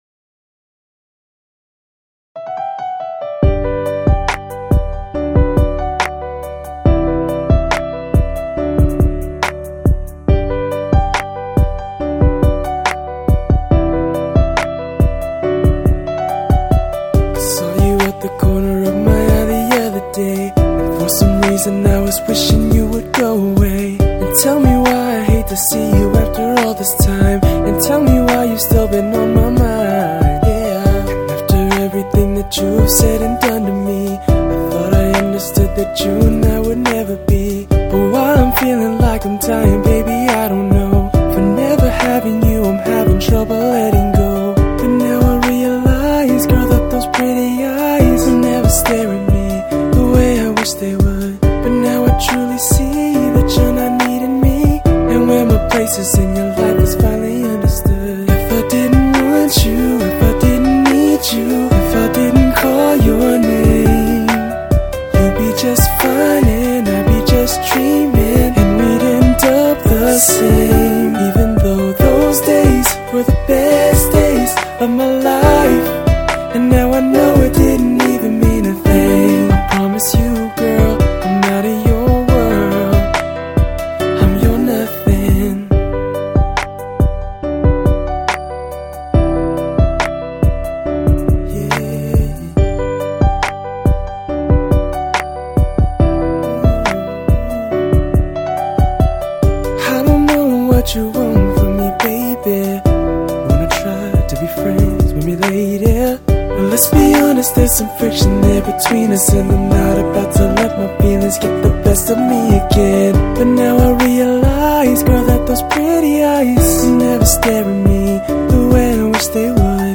"Nothing." (pop/R&B)